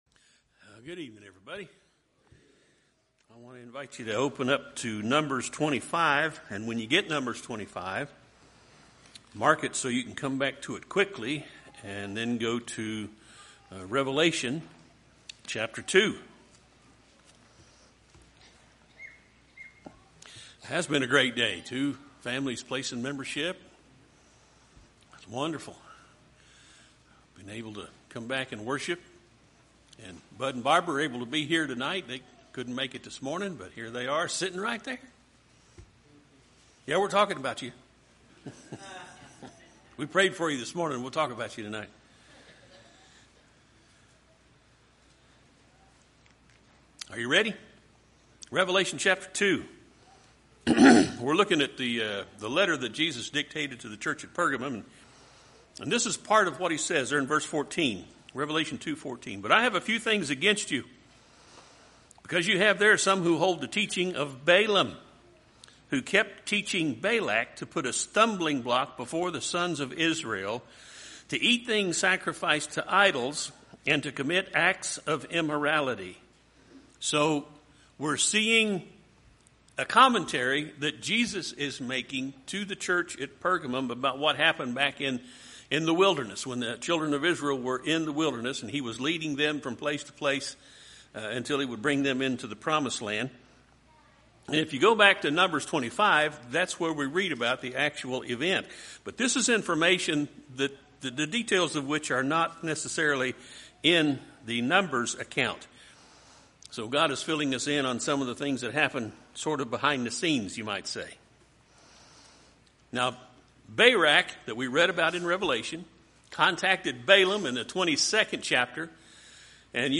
Podcasts Videos Series Sermons Is That Christian?